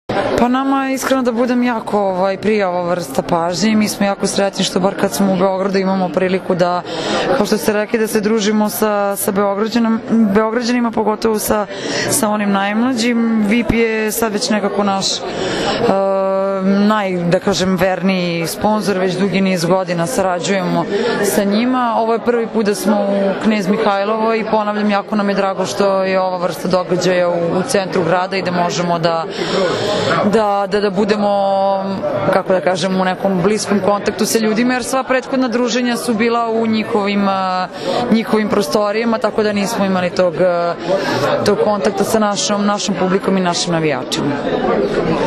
IZJAVA MAJE OGNJENOVIĆ, KAPITENA SENIORKI SRBIJE